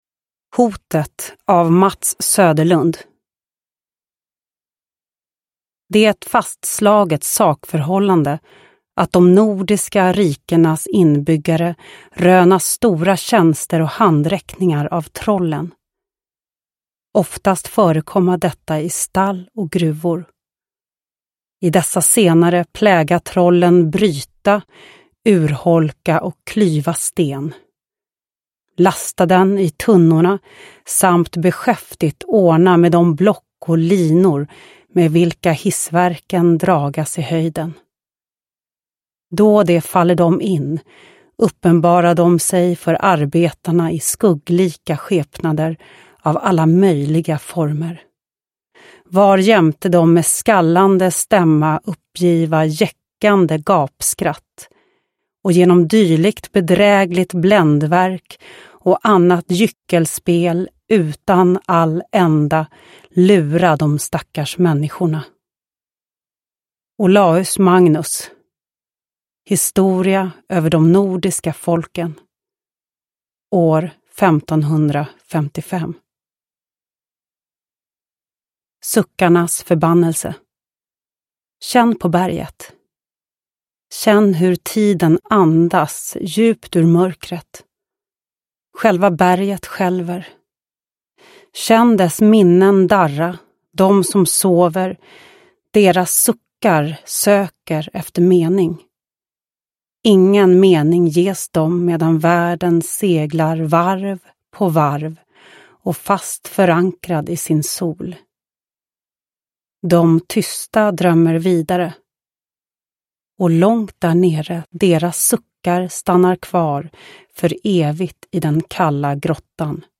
Hotet – Ljudbok – Laddas ner
Uppläsare: Lo Kauppi